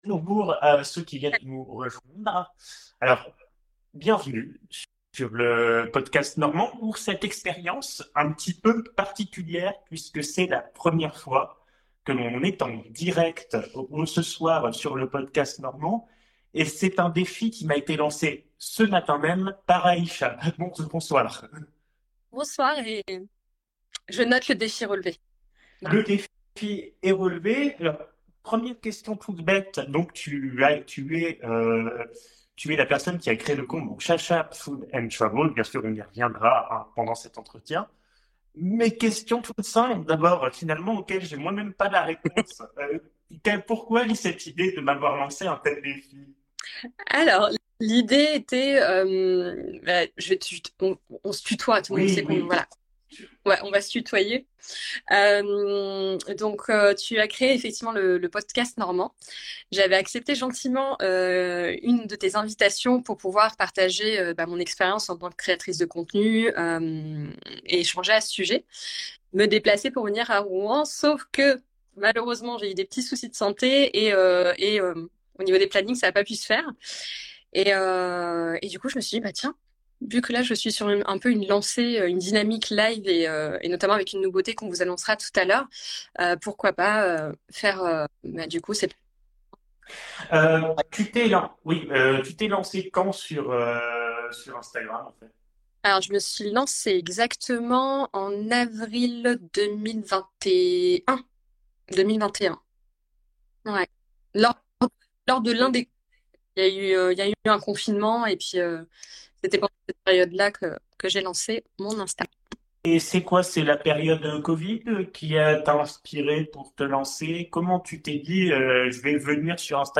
Forcément quand on marche beaucoup en voyage, eh bien ça creuse, et on espère que cette petite discussion va vous mettre en appétit ! Cet épisode, est une rediffusion d’une émission diffusée en direct sur Instagram, Il n’y a donc pas de montage, cet épisode est diffusé dans les conditions du direct.